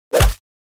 Download Free Fight Sound Effects
Fight